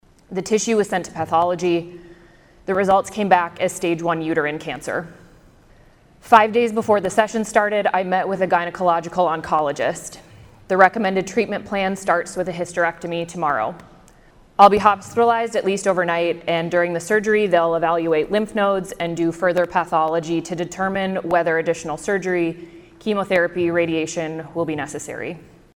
DREY ANNOUNCED HER RECENT DIAGNOSIS MONDAY IN REMARKS DELIVERED ON THE FLOOR OF THE IOWA SENATE.